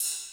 Cymbals Ride 05.ogg